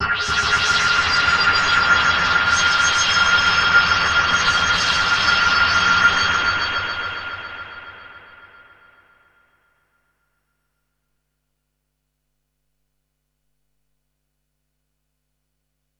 Here’s a test with the same 3 sounds on both hardware and software, all recorded into Digitakt II.